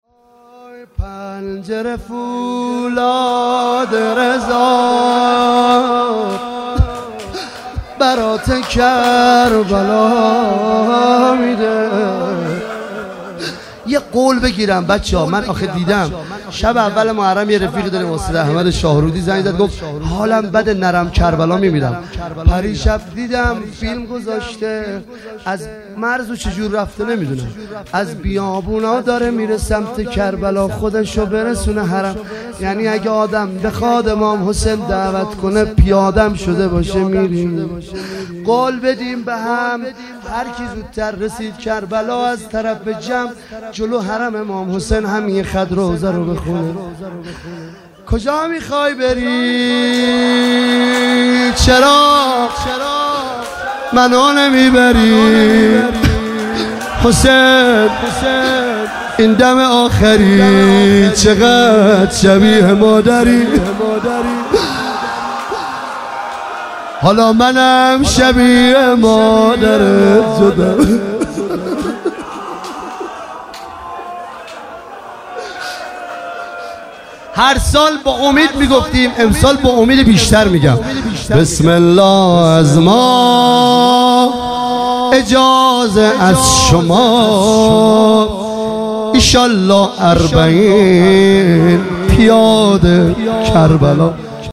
شب هشتم محرم ۱۳۹۹
روضه پایانی پنجره فولاد رضا برات کربلا میده